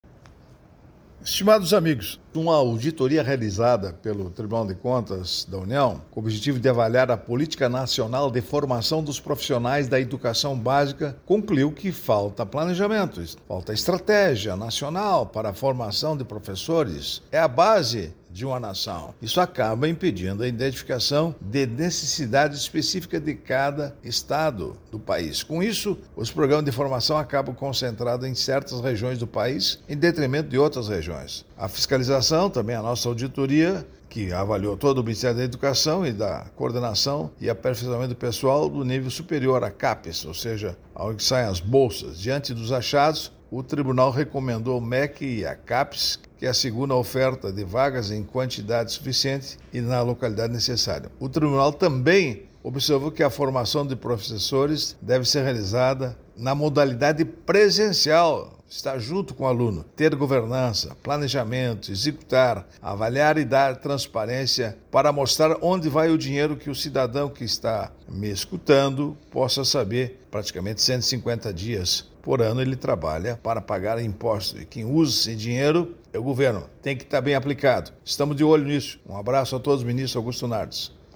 Comentário de Augusto Nardes.